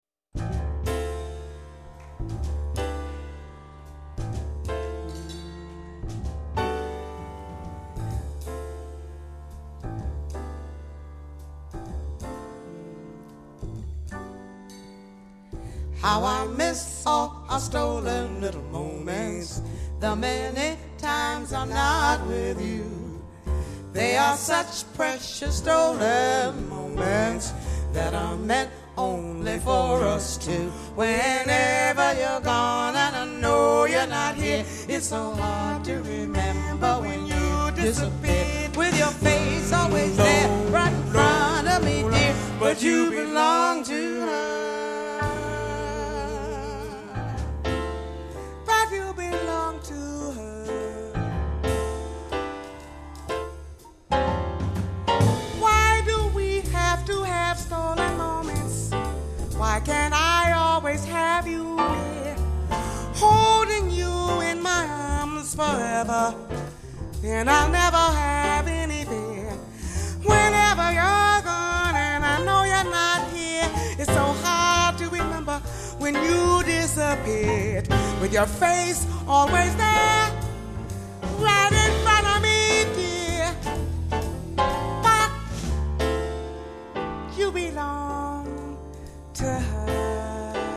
“muggiscono” note da contraltoni
duetto donna-donna